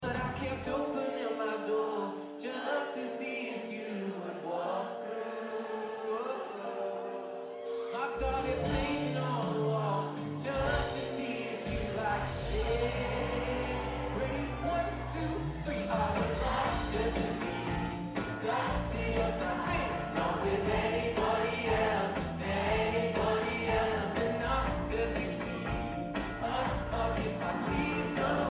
live at Shepherds Bush Empire, London